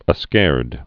(ə-skârd)